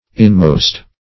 Inmost \In"most`\, a. [OE. innemest, AS. innemest, a double